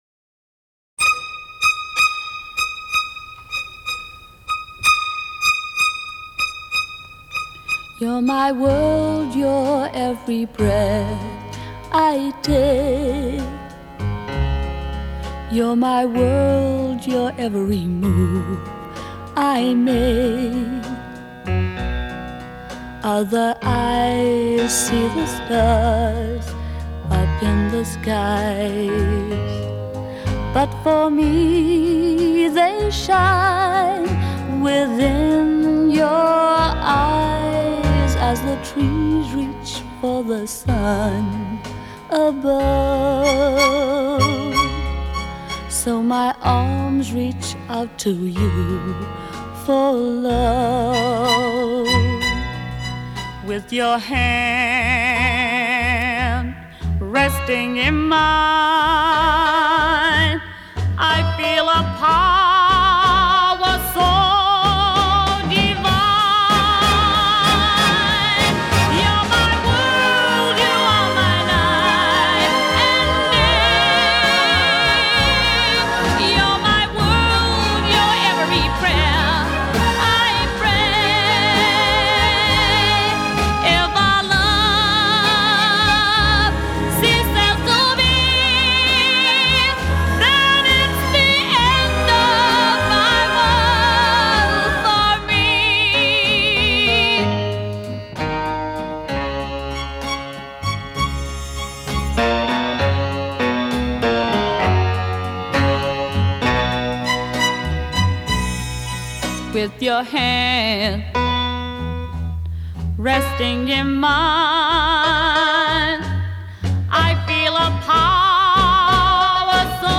brassy belt